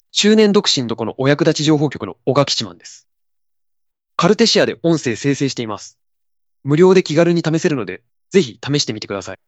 テキスト入力します（日本語でOKです）。
出来た音声がこちらです。
漢字を読むのは苦手のようです。